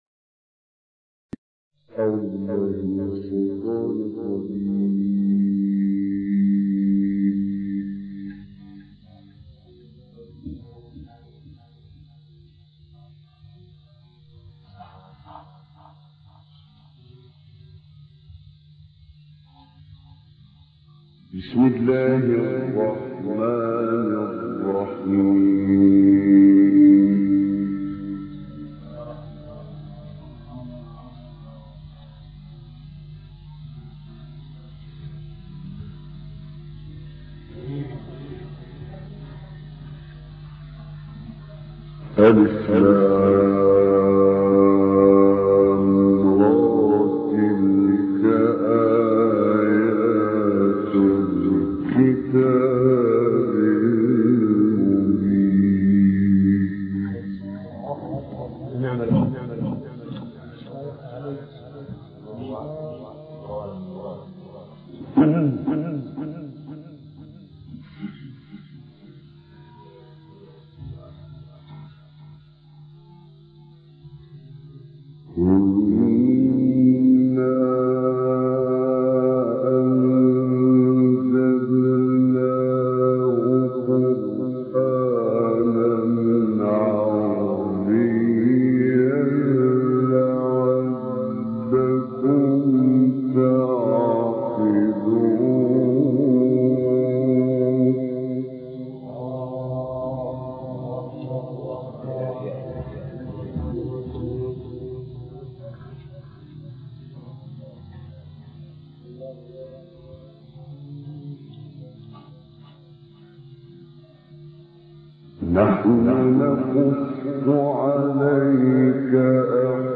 سوره : یوسف آیه: 1-4 استاد : محمد عمران مقام : مرکب خوانی(بیات * رست) قبلی بعدی